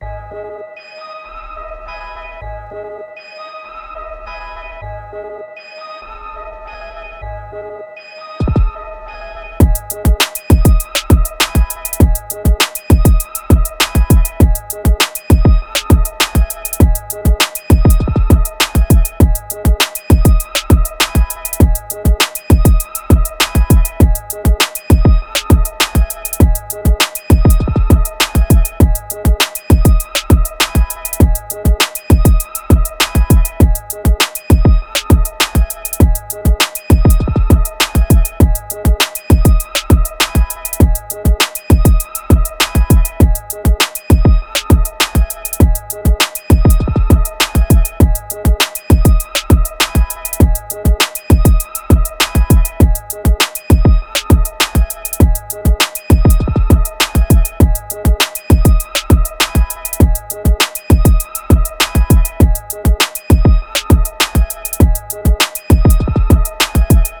Trap, Hip Hop
G# Minor